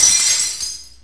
Destructions soundbank 4